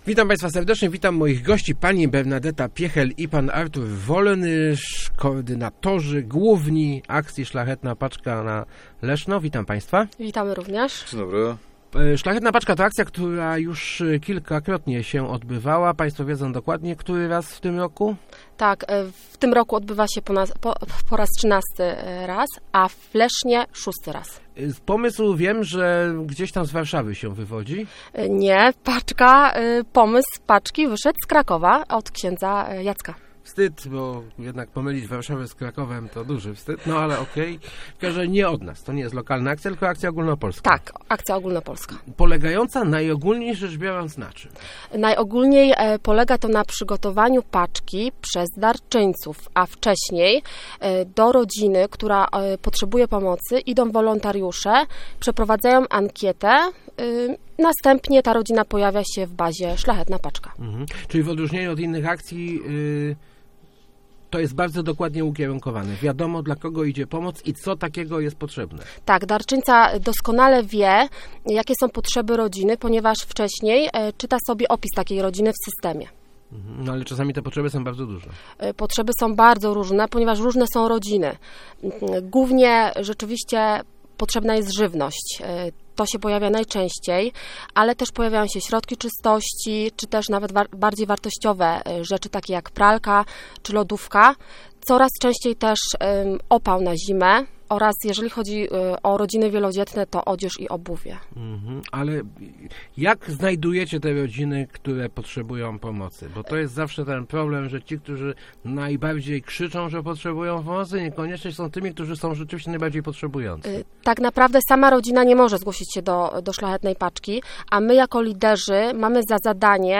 mówili w Rozmowach Elki